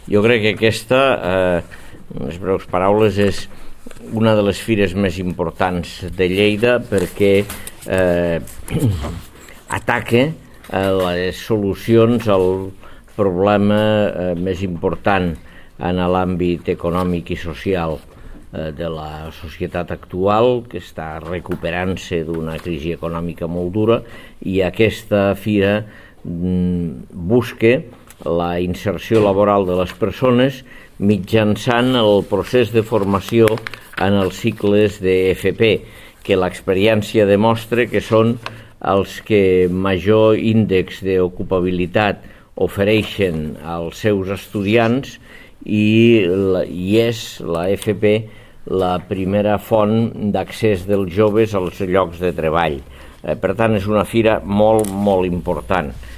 tall-de-veu-dangel-ros-sobre-la-importancia-de-la-fira-formacio-i-treball